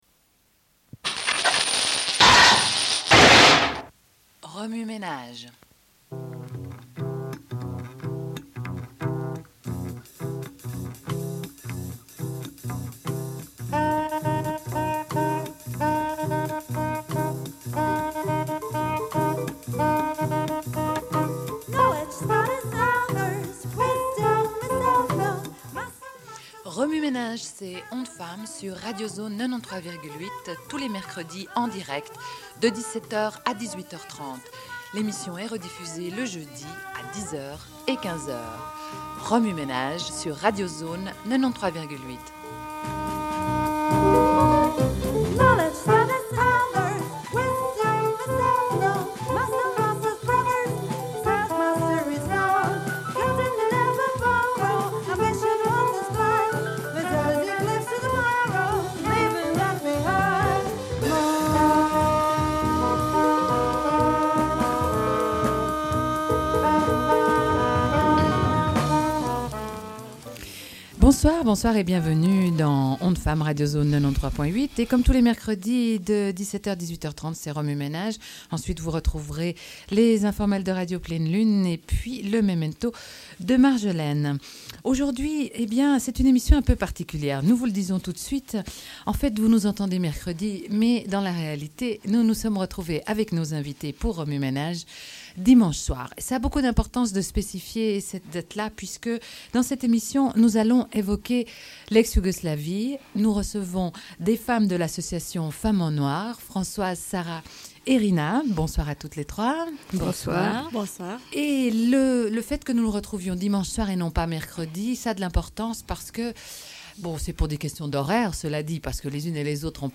Une cassette audio, face A31:04